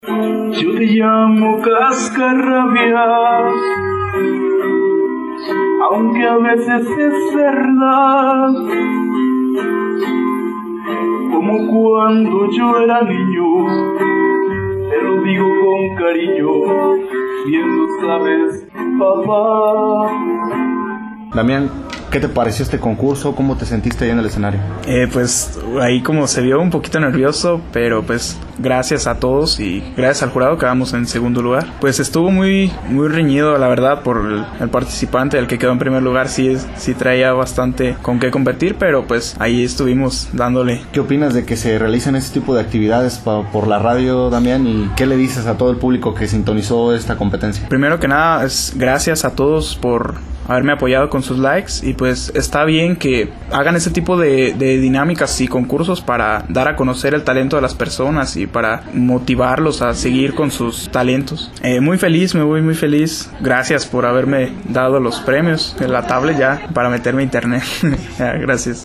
CONCURSO CON MOTIVO DEL DIA DEL PADRE EN LA RADIO
En otro asunto, tenemos que agradecer a todos aquellas personas que participaron mandando su video y también aquellas personas que votaron por el que más les gusto, la final del concurso de canto en honor al día del padre se llevó a cabo aquí en las instalaciones de la radio y los 4 participantes con más likes en redes sociales cantaron completamente en vivo para competir por el primer lugar.
quien se acompañó al son de su guitarra